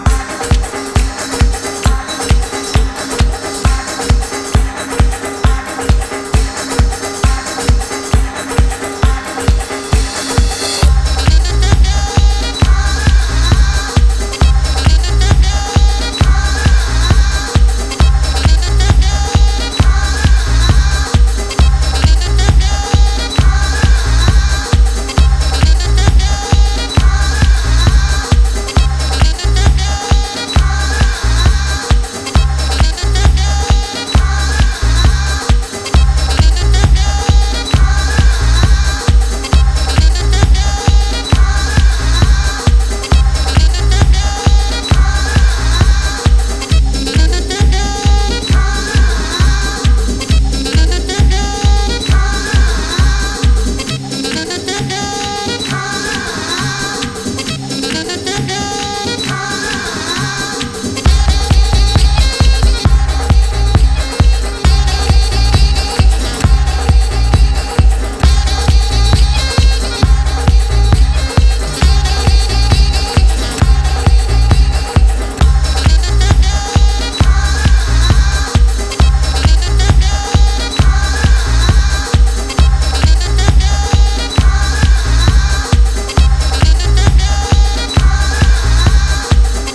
呪術的なパーカッションや歌唱を昇華したダークなトライバル・テクノ